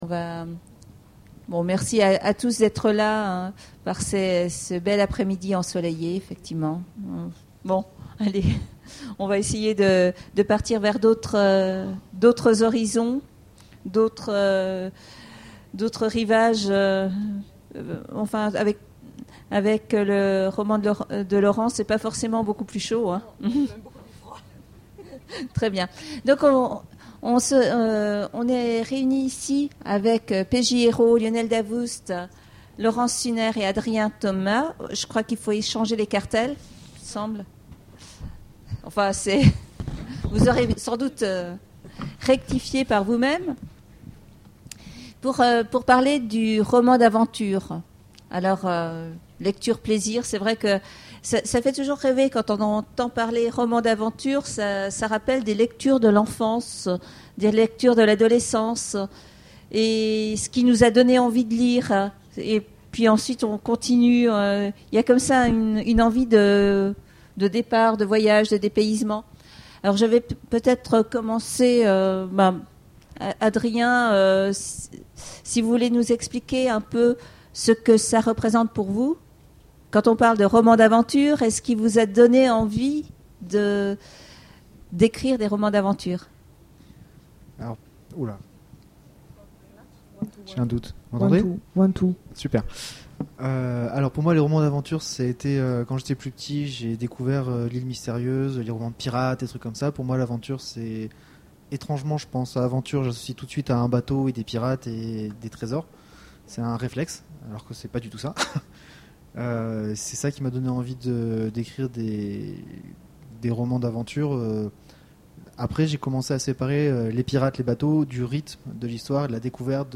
Imaginales 2013 : Conférence Romans d'aventure...